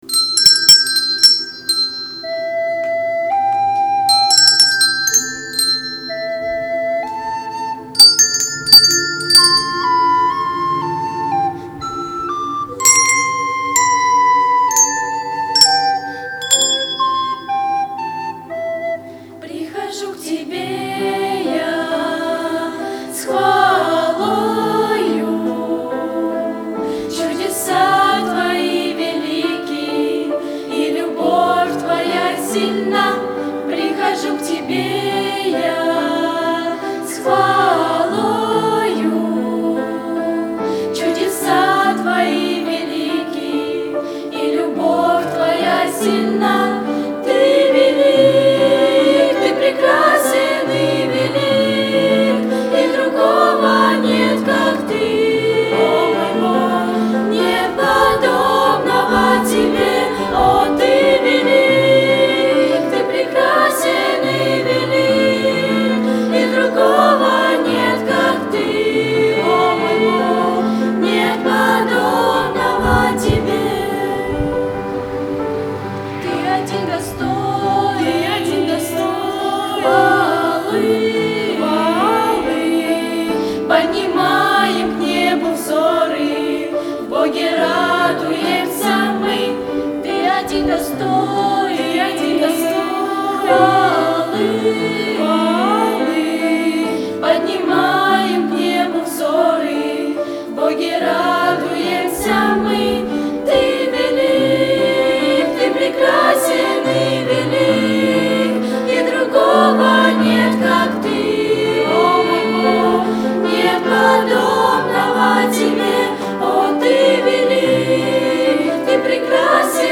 on 2014-07-08 - Фестиваль христианской музыки и песни
подростковый_ансамбль__Костанай
61513-Прихожу_к_Тебе_я_-_подростковый_ансамбль__Костанай_.mp3